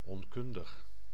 Ääntäminen
IPA: [i.ɲɔ.ʁɑ̃]